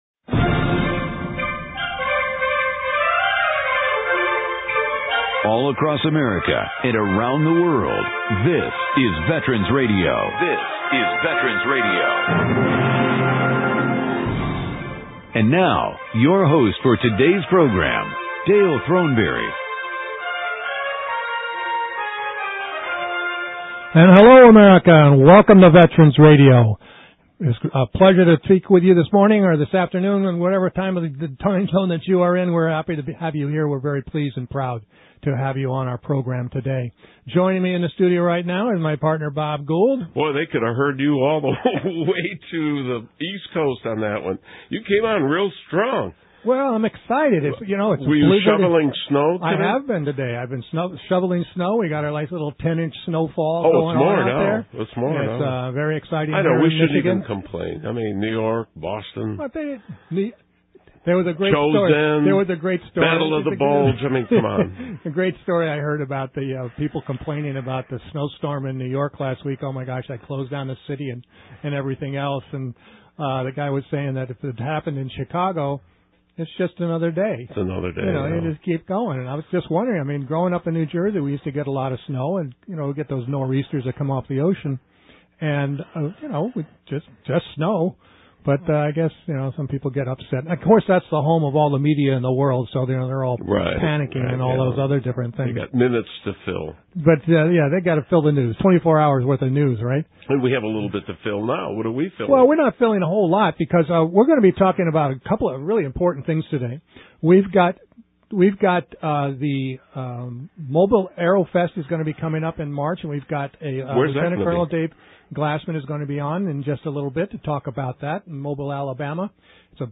Join us as we learn more about the WCC Veterans Office and we talk with some student veterans in our studio.